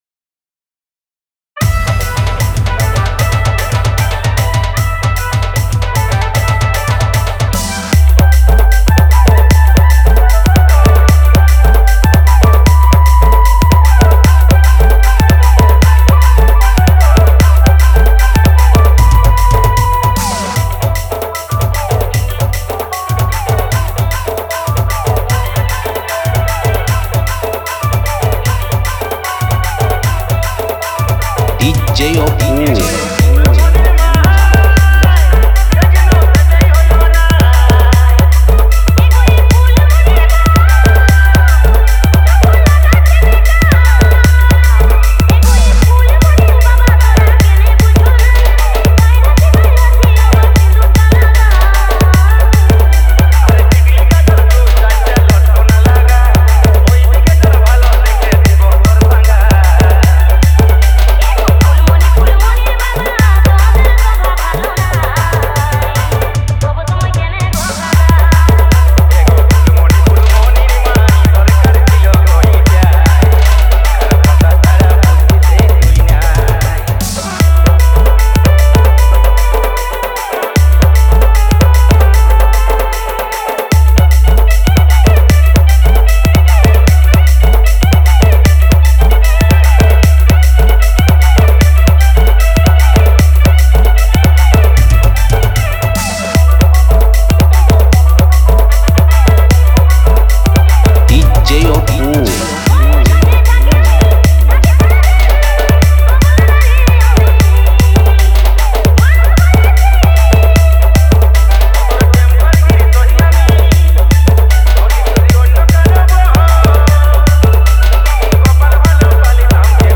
Purulia Roadshow Matal Dance Mix